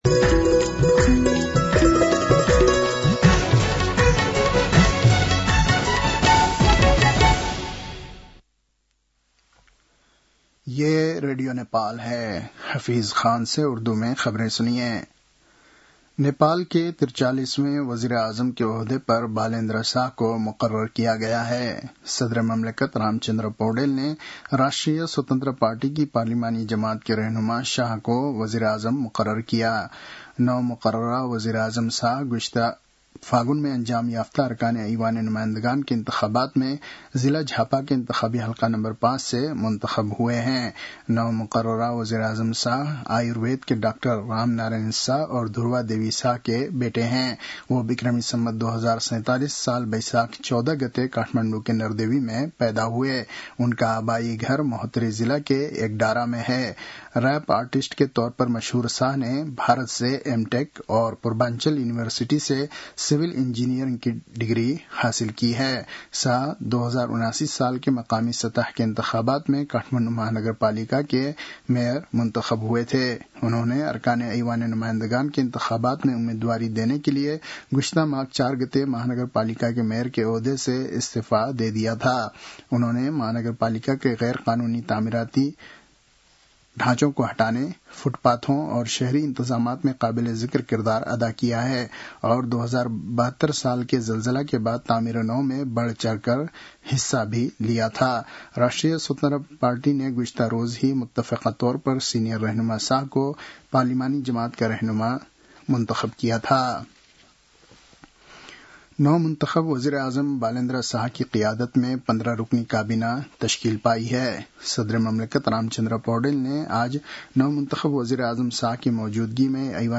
उर्दु भाषामा समाचार : १३ चैत , २०८२